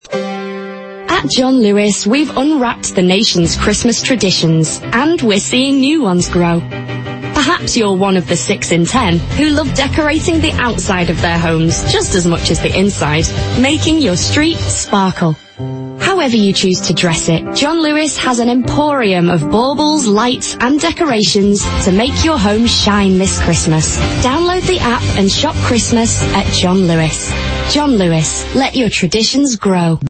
This campaign prompts you to ponder your own Christmas traditions, as well as doing a pretty good job at selling Christmas lights…if that’s your tradition of course. The original composition in this campaign is brilliant too, talk about feel-good.